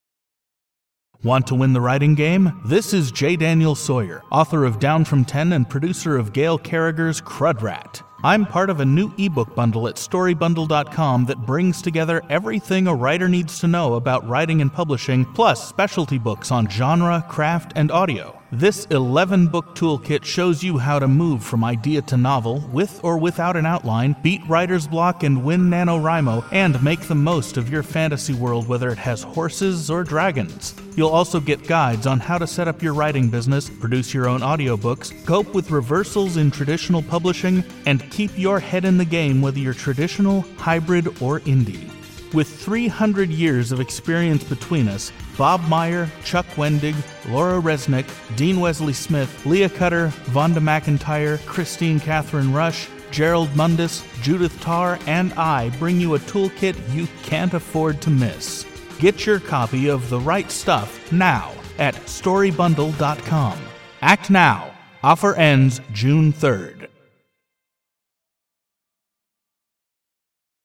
Promo two (male voice, 74 secs)